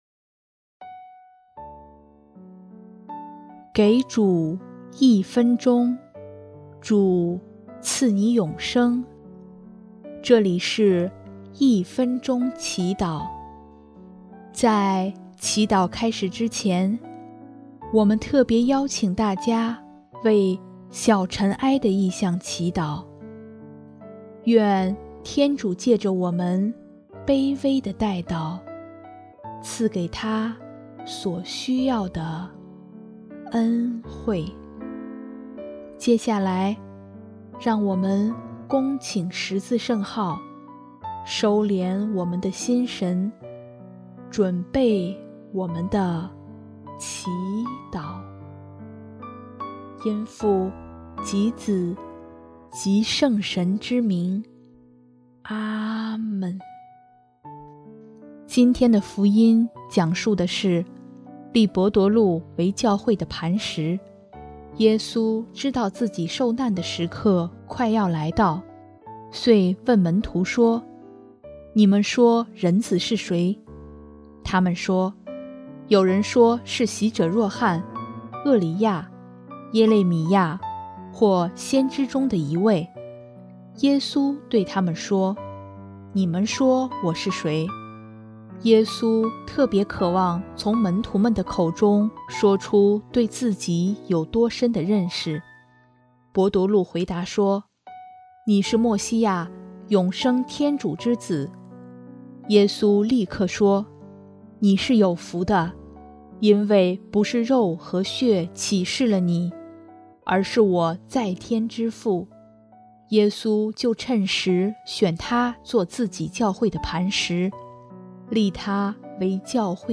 音乐：主日赞歌《磐石》（小尘埃：求主让教会复兴）